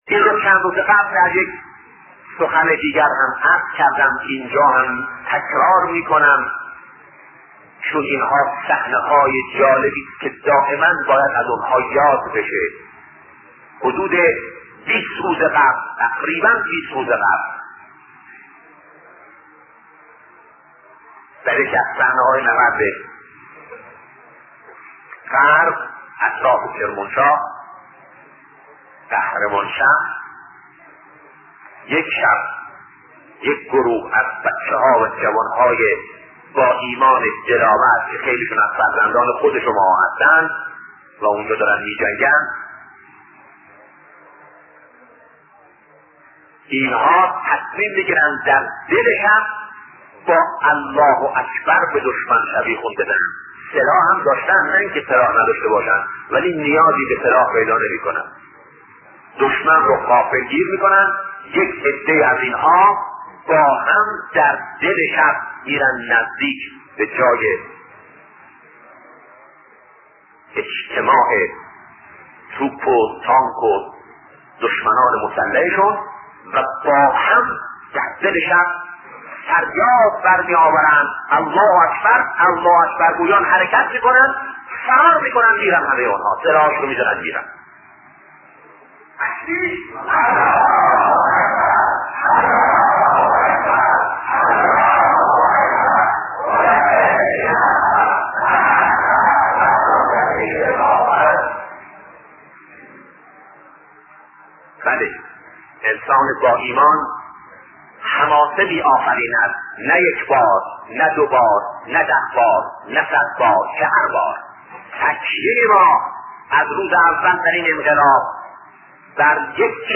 صوت شهید بهشتی(ره)- با موضوع رهروان راه حسین(ع) در هیئت انصارالحسین-بخش‌دوم